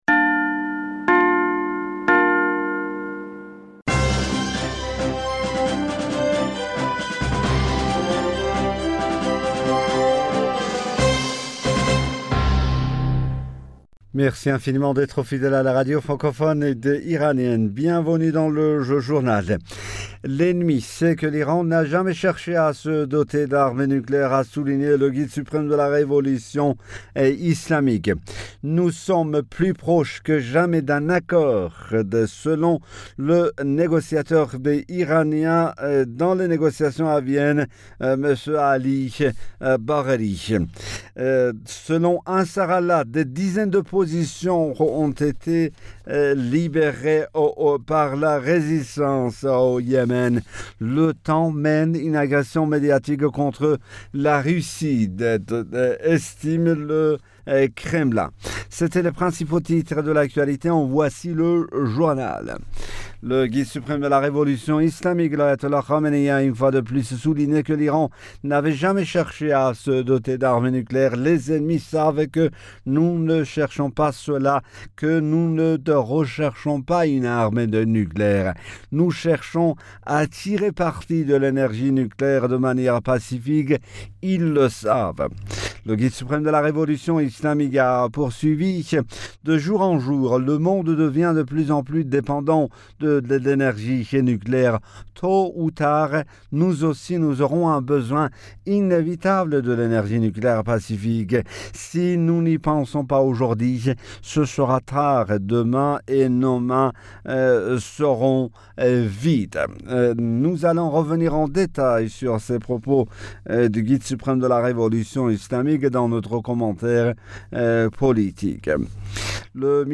Bulletin d'information Du 18 Fevrier 2022